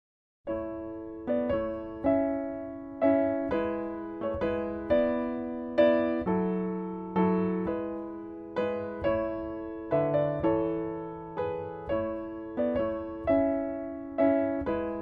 Classical, Piano